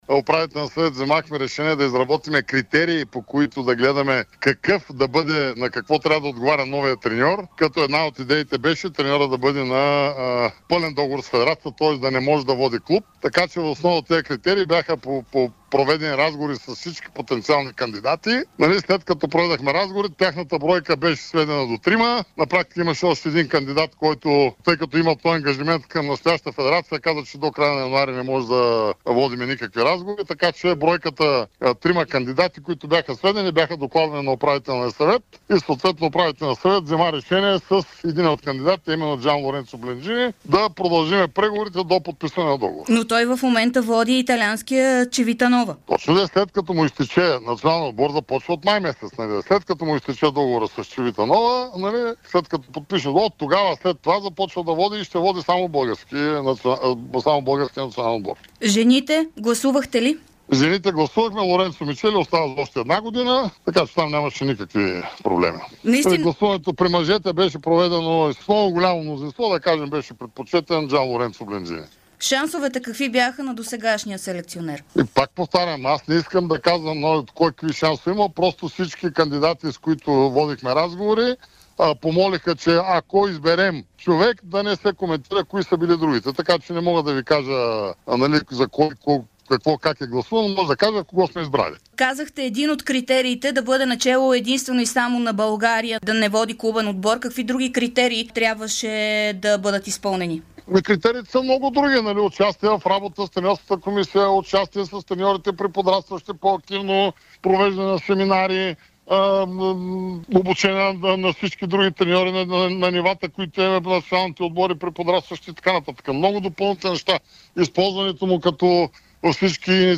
Президентът на Българската федерация по волейбол Любо Ганев даде ексклузивно интервю пред Дарик радио и dsport във връзка с миналия Управителен съвет на БФВолейбол, който реши новият селекционер на мъжкия национален отбор по волейбол да бъде Джанлоренцо Бленджини.